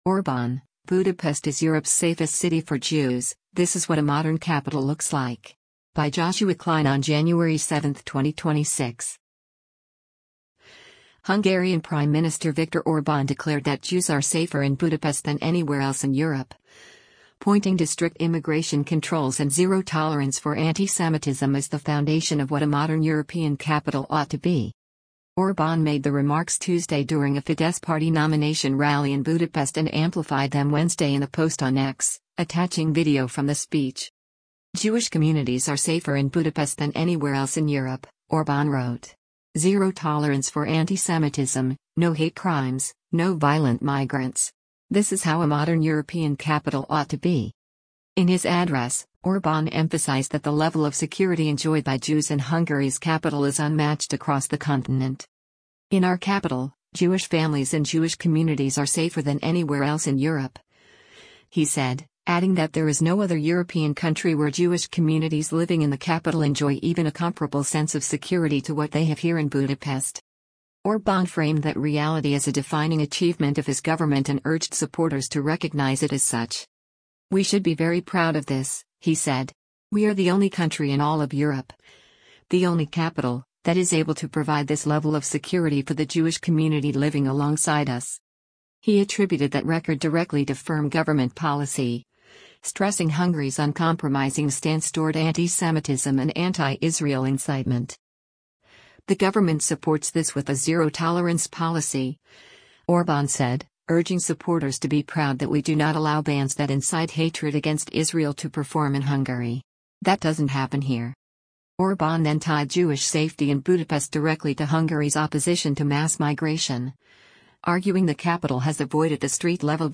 Orbán made the remarks Tuesday during a Fidesz party nomination rally in Budapest and amplified them Wednesday in a post on X, attaching video from the speech.